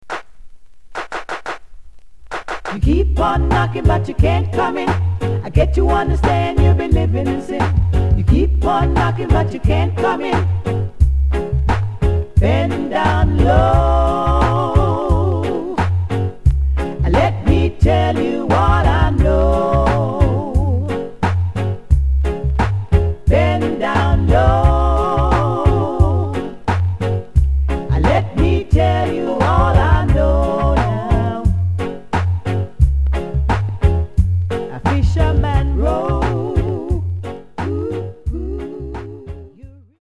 Genre: Reggae